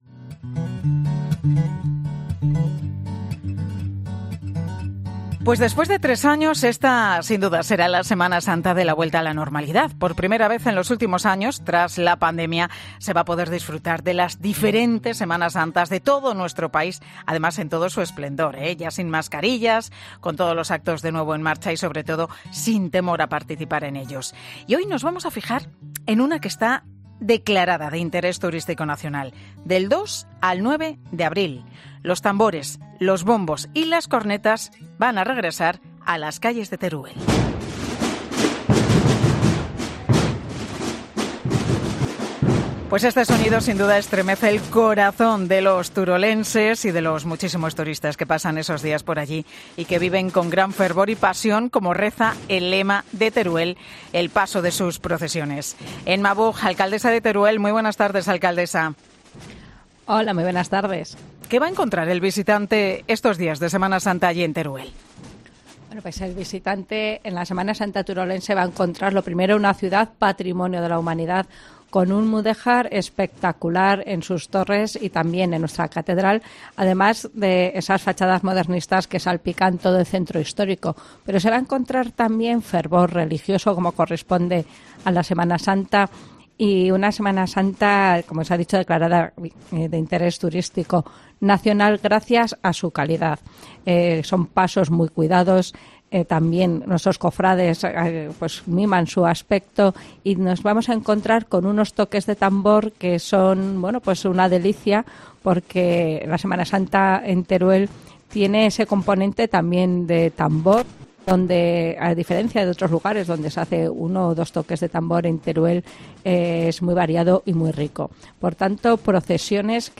En 'Mediodía COPE' hablamos con Emma Buj, alcaldesa de Teruel, que nos da las claves de cómo será una de las Semanas Santas más especiales en años